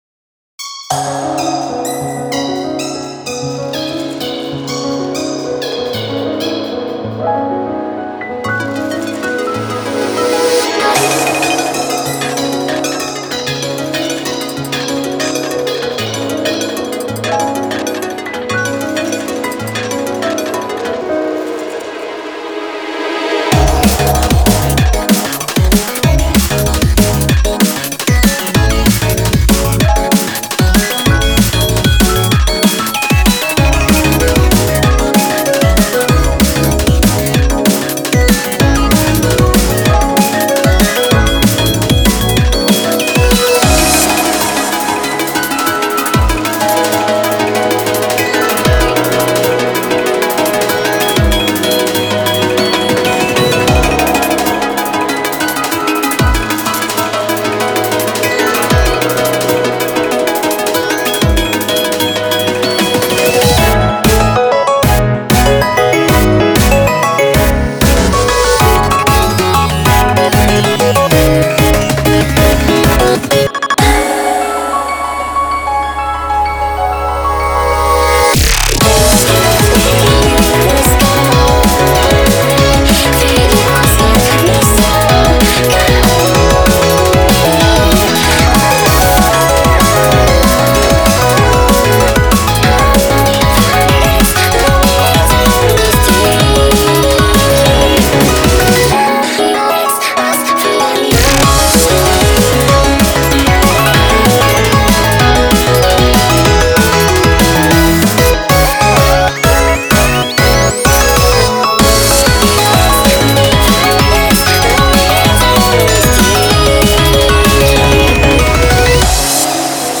BPM191
Audio QualityPerfect (High Quality)
Genre: ARTCORE.